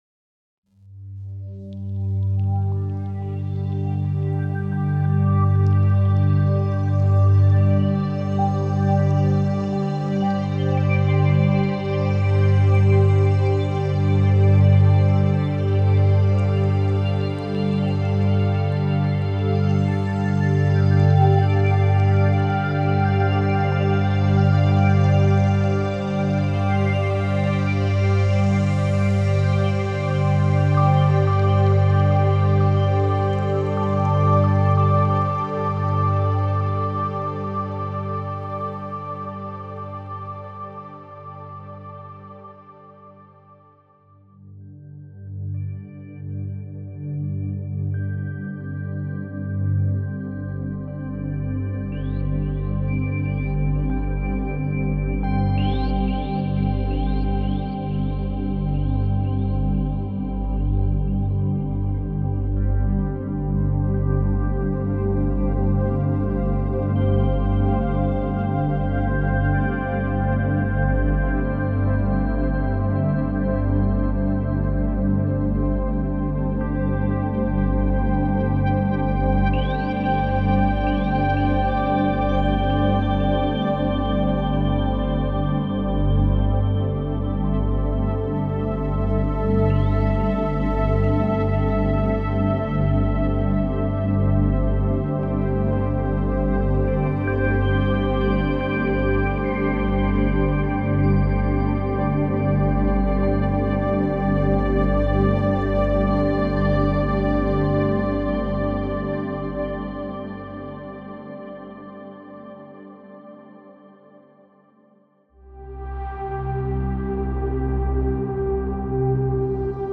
Ambient Chill Out / Lounge Cinematic / FX Multi-genre
Immerse yourself in rich, evolving pads perfect for ambient, cinematic, and chillout productions, or effortlessly integrate these versatile presets into any genre to add a touch of sonic depth.
* The video and audio demos contain presets played live and recorded direct from Spire. All sounds are from Chronosphere and only a light limiter has been used for the demos.